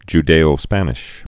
(j-dāō-spănĭsh)